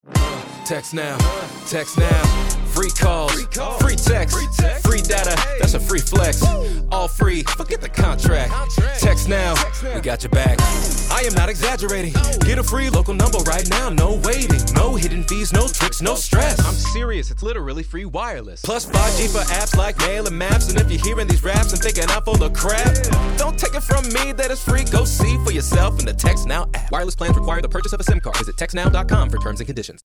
Audio Ads
Formats: Streaming Audio (Music + Non-Music Variants), Accompanying Banner Ads